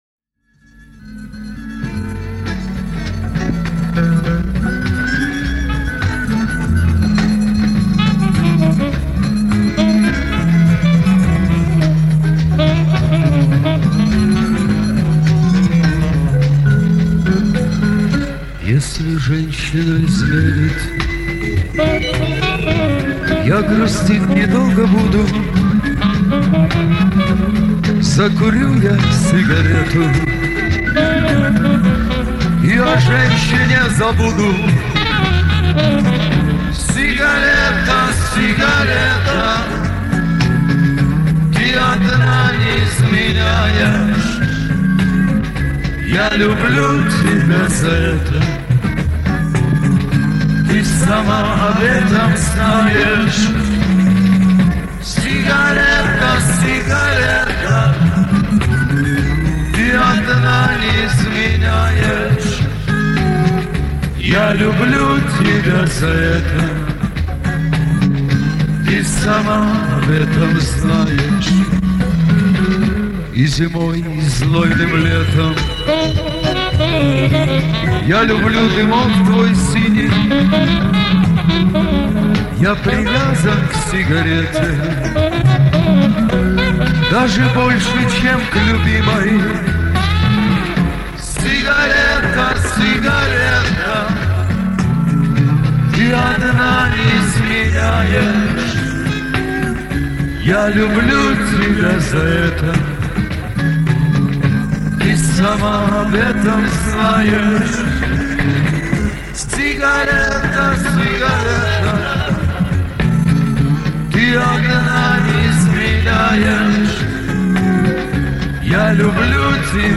Явно армянский акцент как и у камертоновских записей у одного из вокалистов, вроде не Вартинер и подобное.